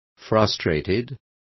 Complete with pronunciation of the translation of frustrated.